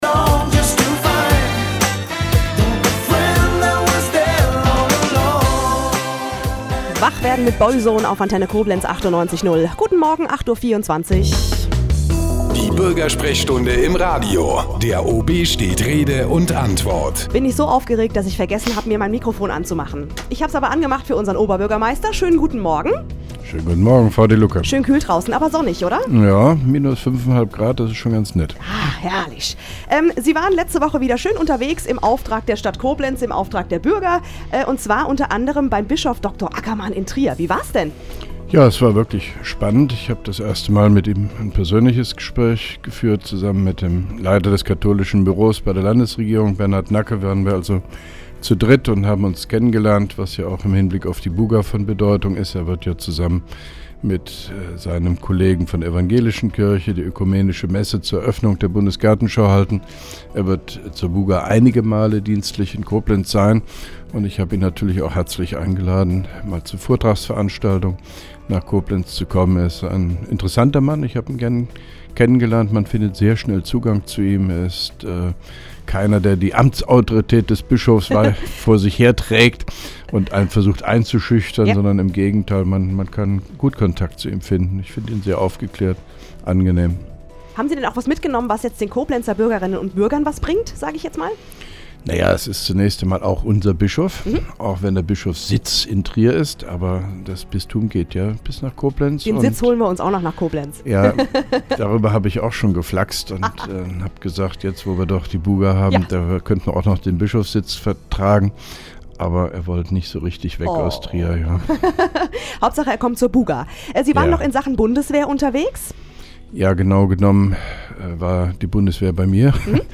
Interviews/Gespräche